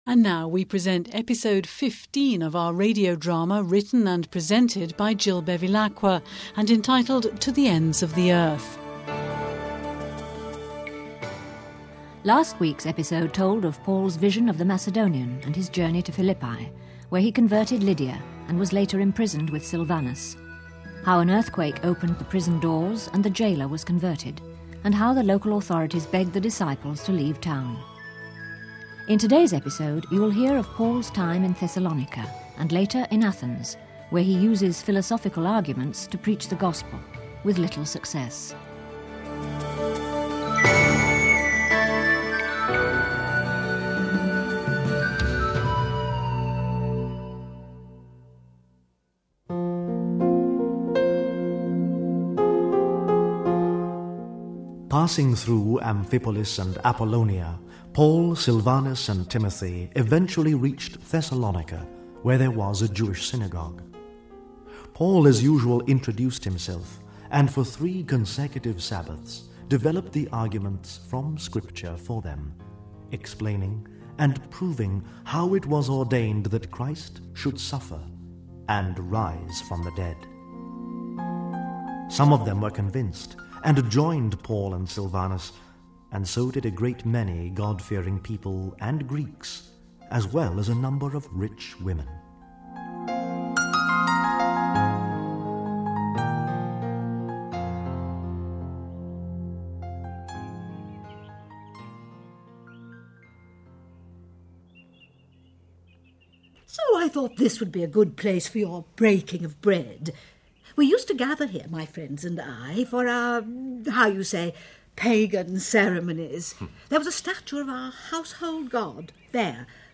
Episode 15 of our weekly radio drama, which during this Pauline Year, takes us back to the times of the first disciples following Our Lord's instruction to take the Good News to the ends of the earth..